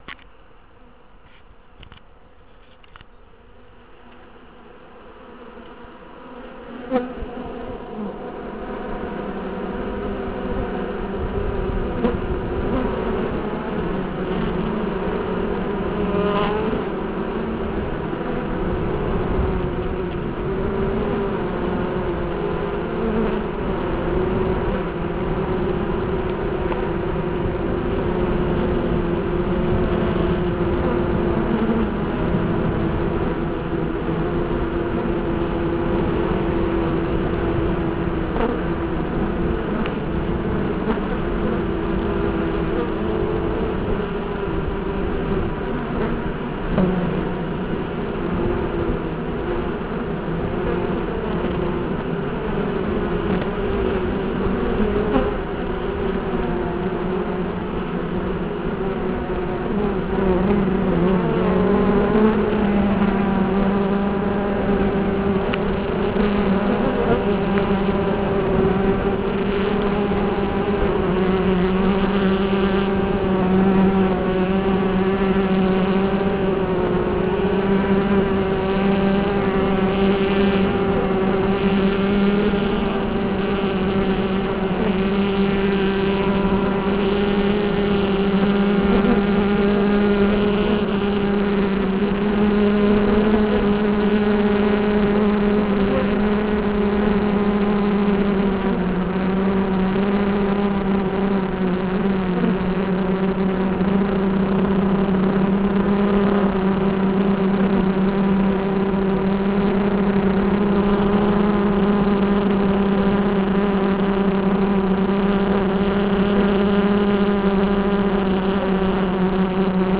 Bienengeräusche der Bienen im ökologischen Dorf der Zukunft in Benroth am Flugloch Werbeflugblatt für meine Imkerei Werbeflugblatt für eine Bienenpatenschaft Fakten zum Thema Dunkle Biene, Poster der GEDB Eine bemalte Hinterladerbeutenfront mit Bauernmalerei aus Slowenien.
flugloch.wav